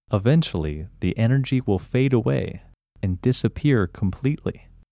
IN – First Way – English Male 20